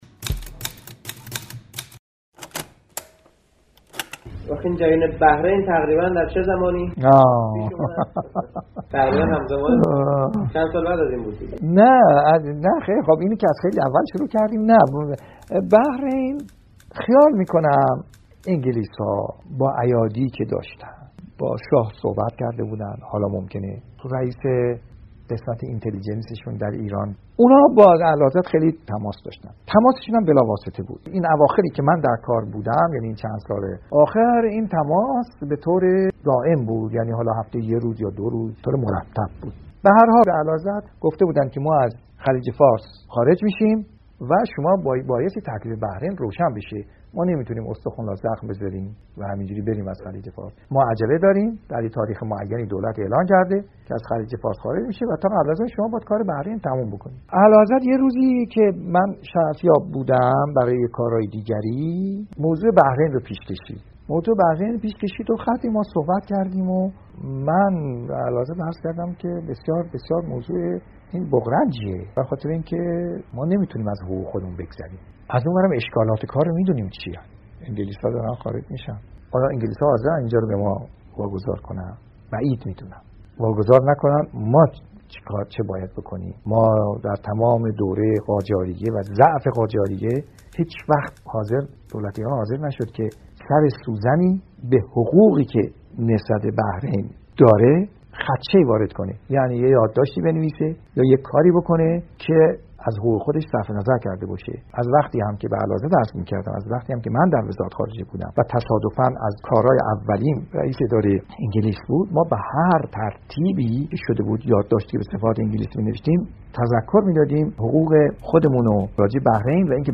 مجموعه مستند «ملاقات با تاریخ» که در شبکه مستند تهیه و تولید شده، برداشتی از پروژه تاریخ شفاهی ایران است، خبرگزاری ایکنا به مناسبت دهه فجر مجموعه‌ای از این مستند را در قالب پادکست تهیه کرده است که دهمین قسمت آن را با خاطره امیر خسرو افشار از جدایی بحرین می‌شنوید.